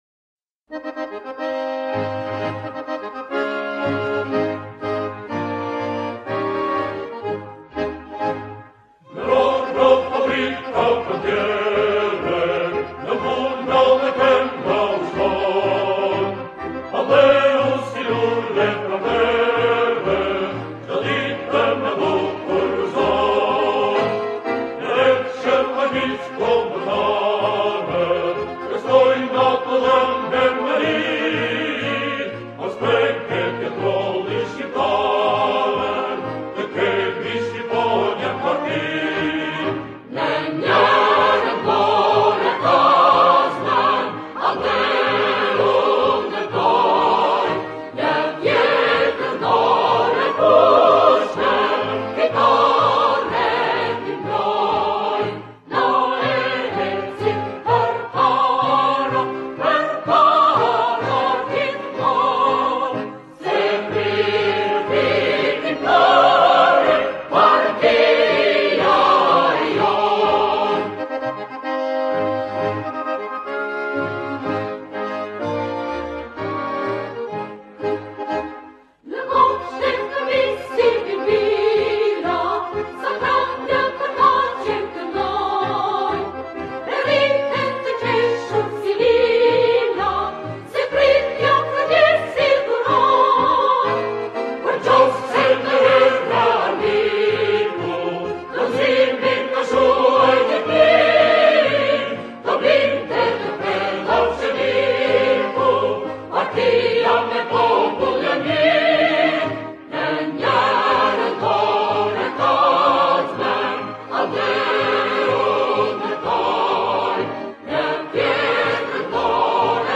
Описание: Албанская революционная песня времён Энвера Ходжи.